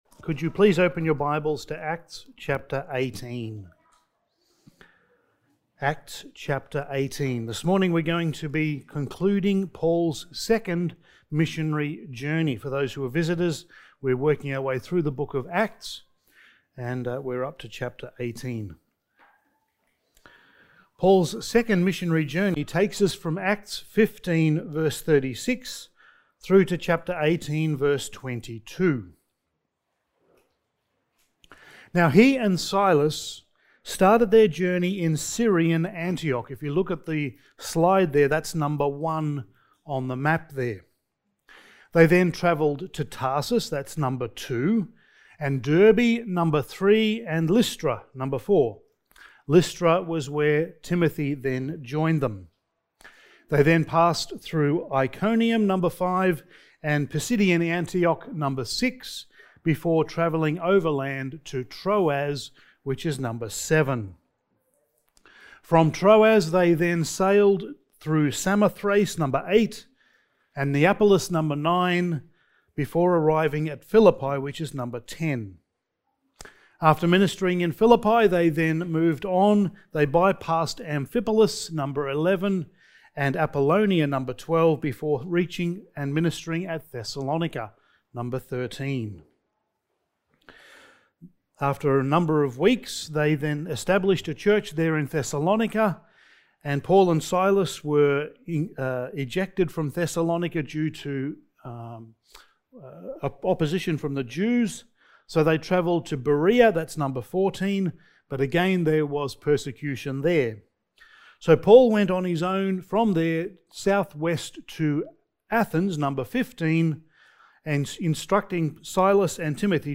Passage: Acts 18:18-28 Service Type: Sunday Morning